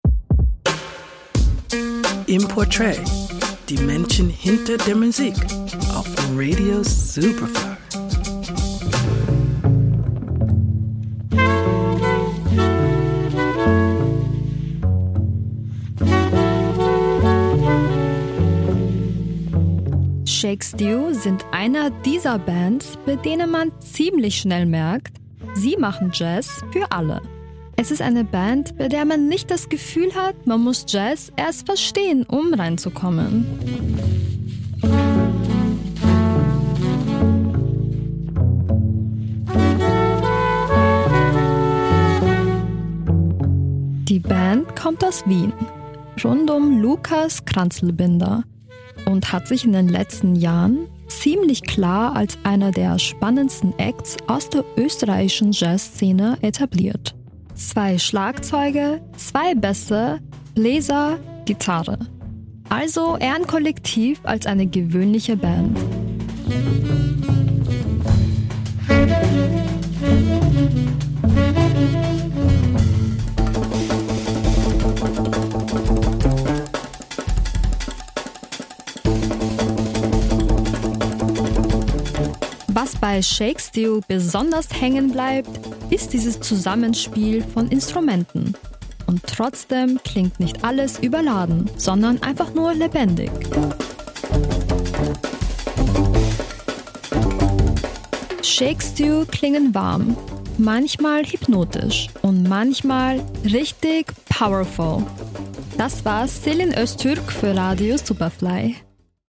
Shake Stew sind eine dieser Bands, bei denen man ziemlich schnell merkt: Sie machen Jazz für ALLE.